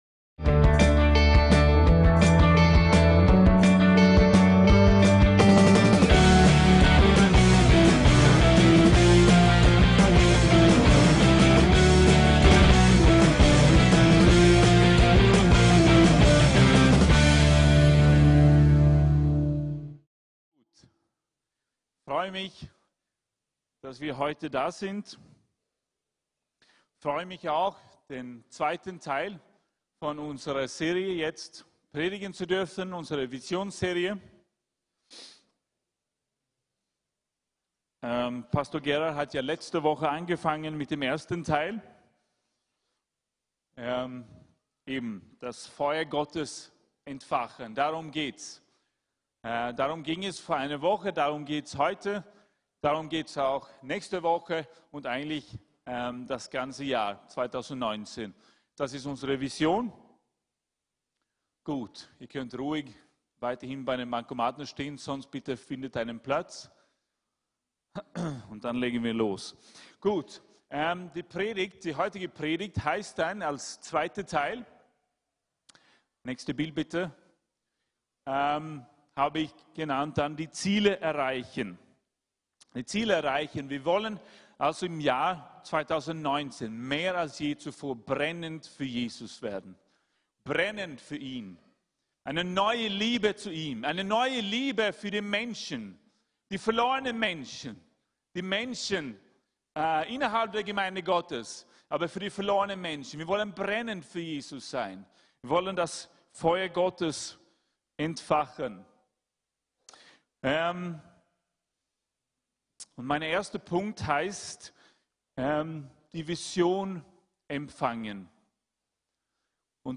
DIE ZIELE ERREICHEN " VISIONSPREDIGT "(2)